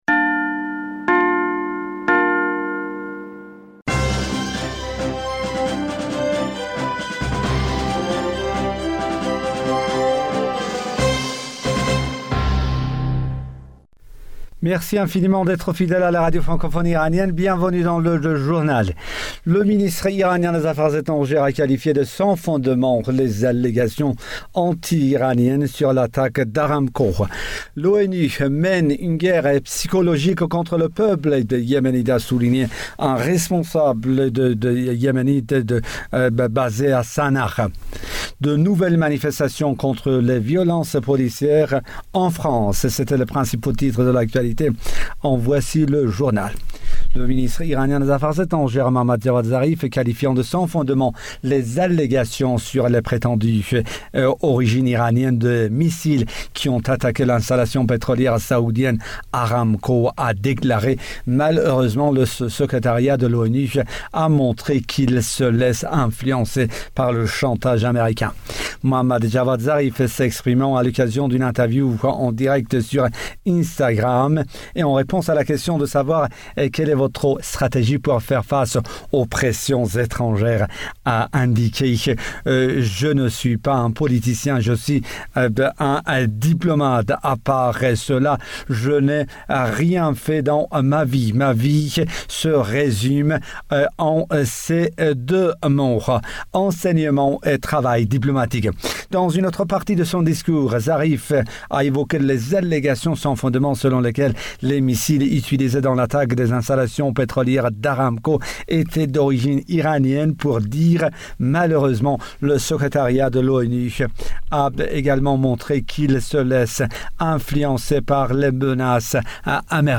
Bulletin d'information du 14 Juin 2020